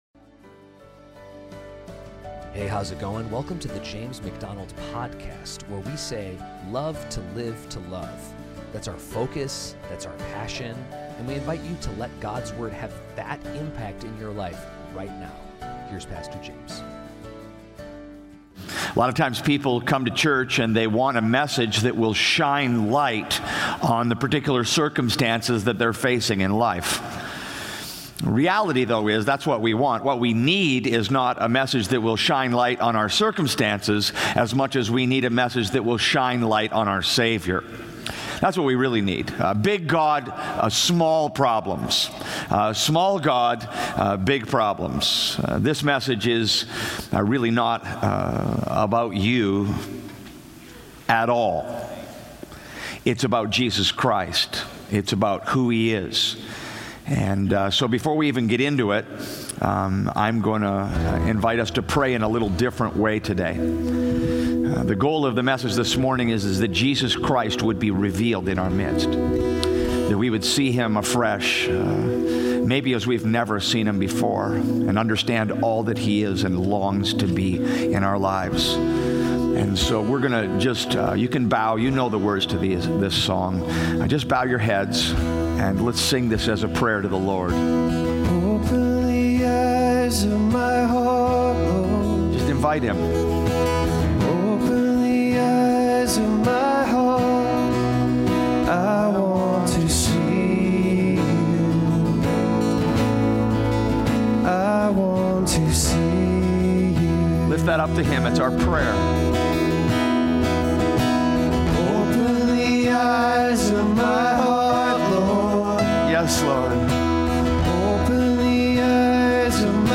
preaches without apology straight from the pages of Scripture, provoking Christians to think and act on their faith.